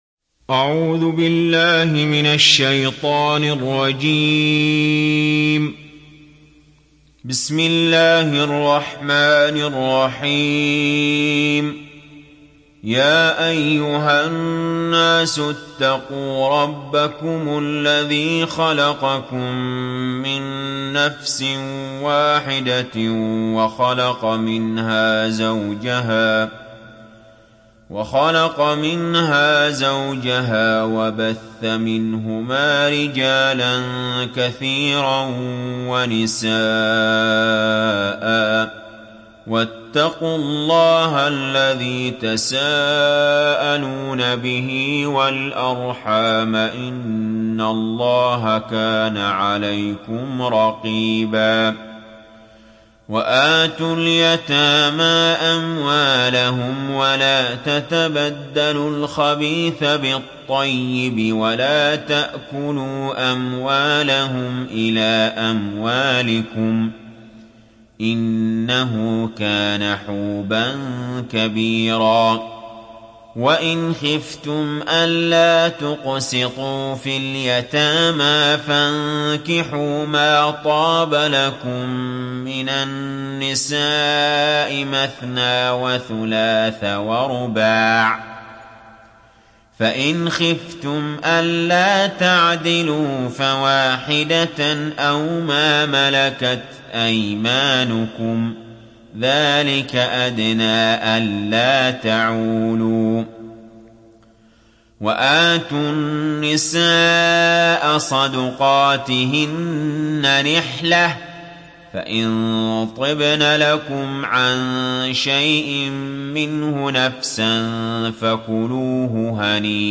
تحميل سورة النساء mp3 بصوت علي جابر برواية حفص عن عاصم, تحميل استماع القرآن الكريم على الجوال mp3 كاملا بروابط مباشرة وسريعة